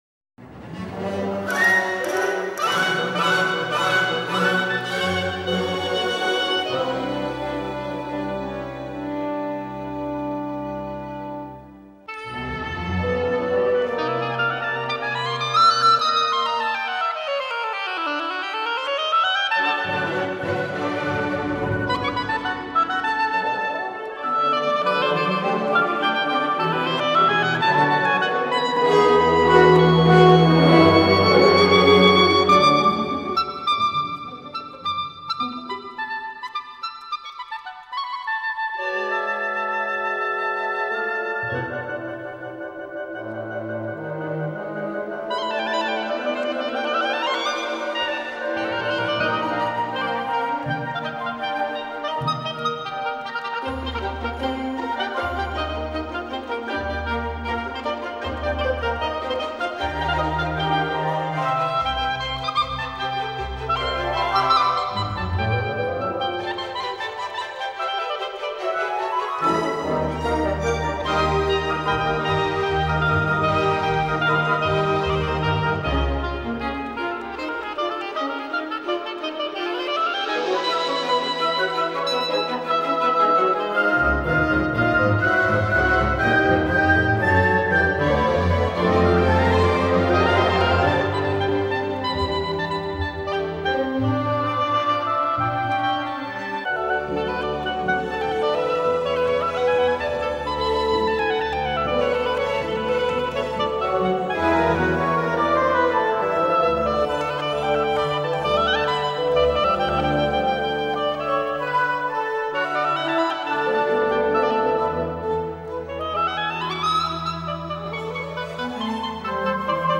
Recorded in London in 1986.